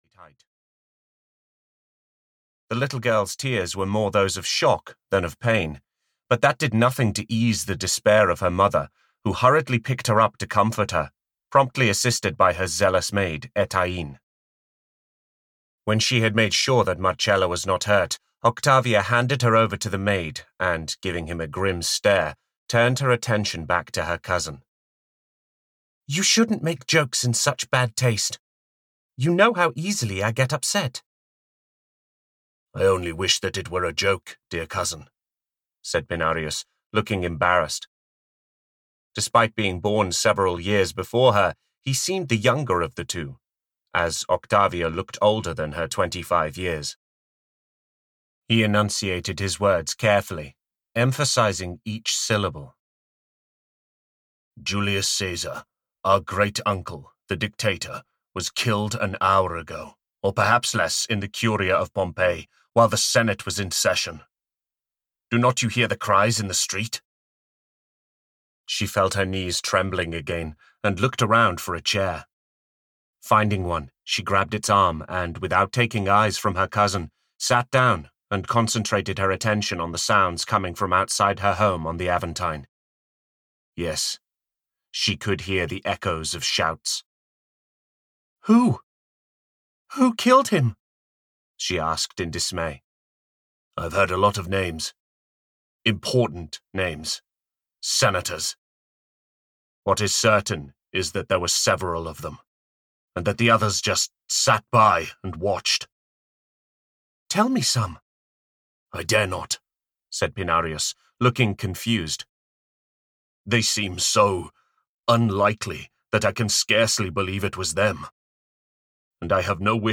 Survival (EN) audiokniha
Ukázka z knihy